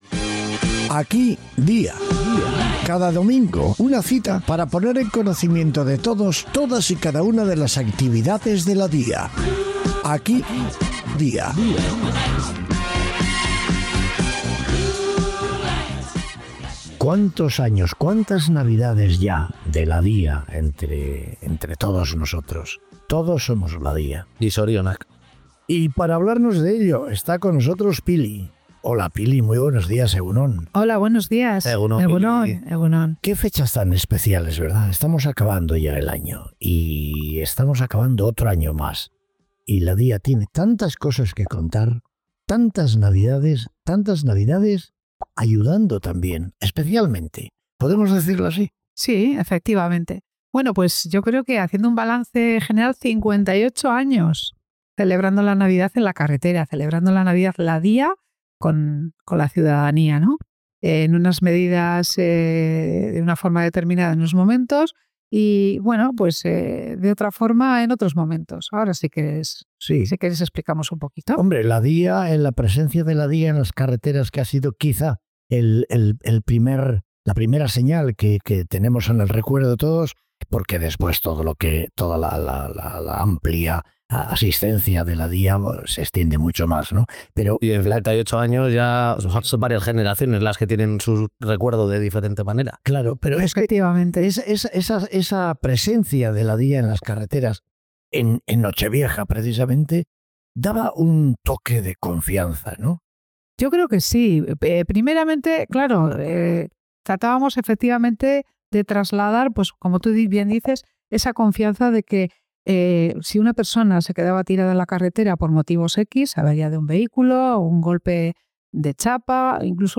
Una charla muy entrañable en unas fechas muy señaladas.